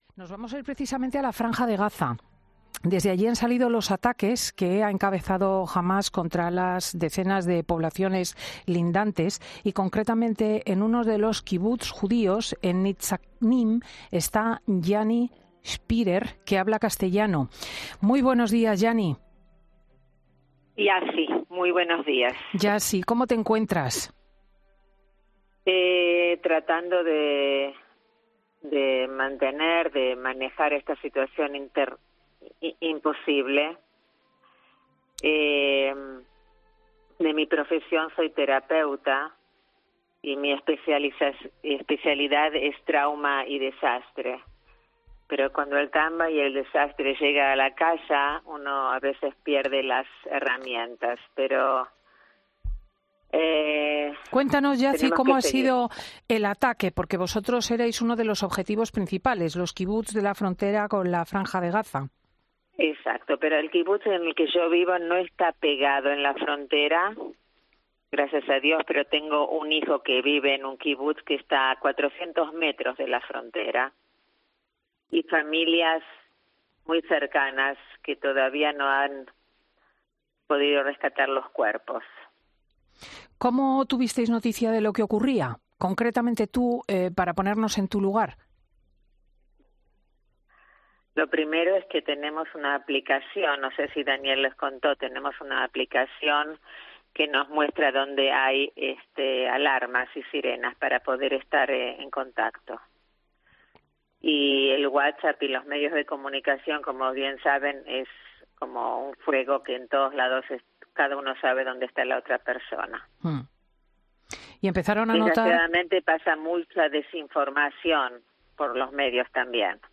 entre lágrimas en COPE
Atiende a la llamada de Fin de Semana y asegura sentir mucho miedo.
Es entonces cuando ha desatado sus lágrimas, ante la impotencia de no poder hacer nada ante la situación y sin saber dónde está parte de su familia ni cuánto tiempo puede durar este conflicto.